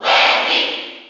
File:Wendy Koopa Cheer NTSC SSB4.ogg
Wendy_Koopa_Cheer_NTSC_SSB4.ogg.mp3